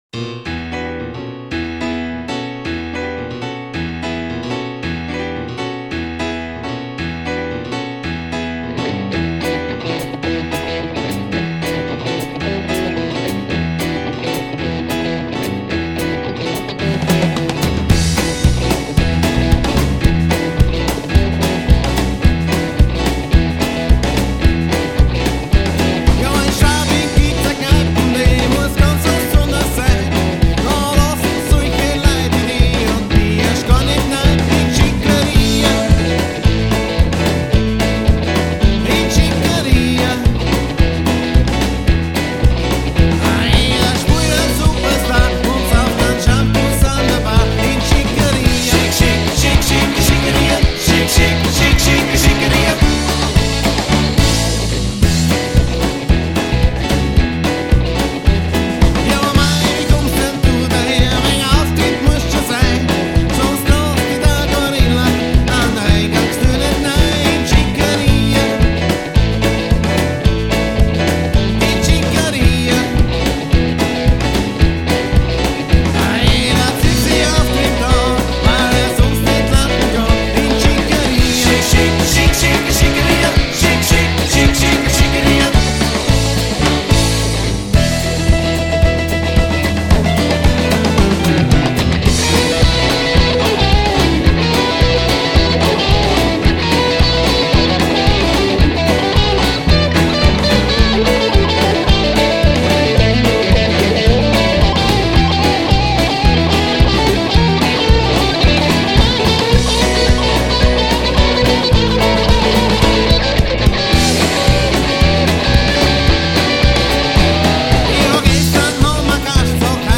5 Musiker